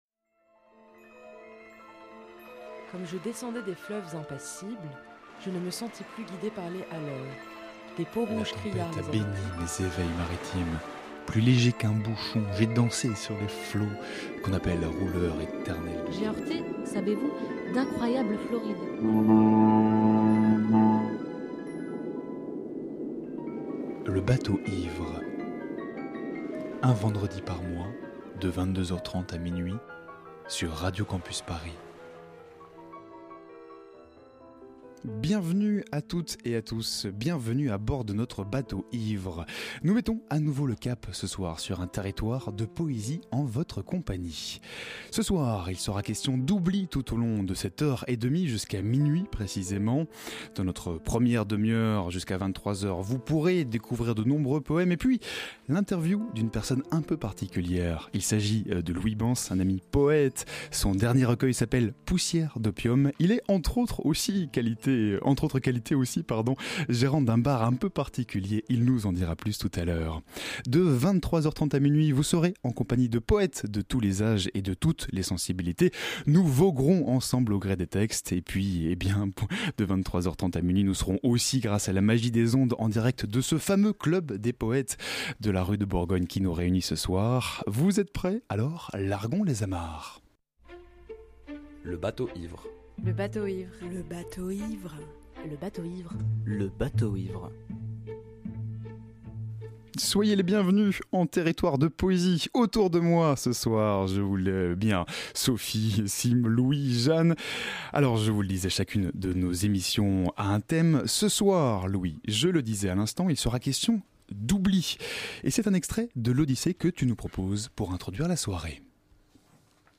Le tout, bien sûr, sera traversé de poésie, de textes déclamés, chantés, murmurés et préparés par toute notre petite équipe.